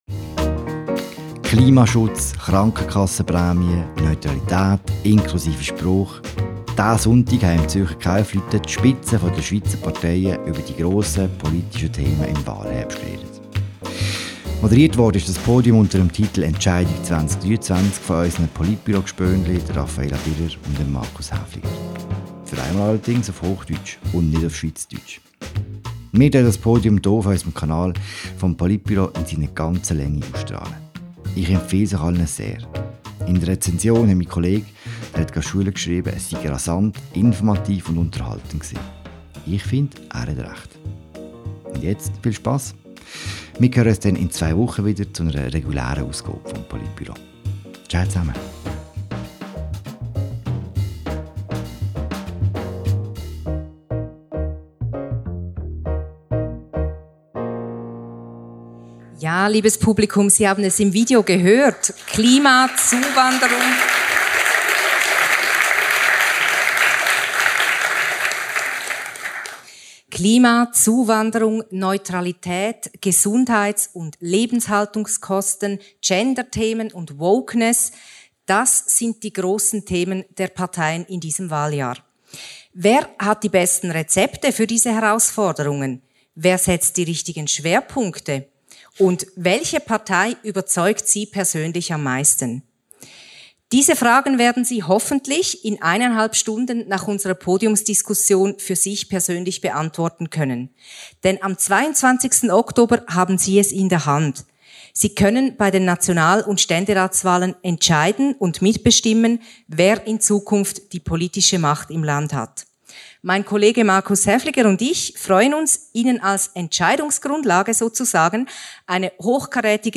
Das Podiumsgespräch verlief rasant - und war trotzdem ziemlich informativ.
Zu Gast in Zürich waren nur Männer (was auch ein Thema war): Thomas Aeschi (SVP), Cédric Wermuth (SP), Thierry Burkart (FDP), Gerhard Pfister (Mitte), Balthasar Glättli (Grüne) und Jürg Grossen (GLP).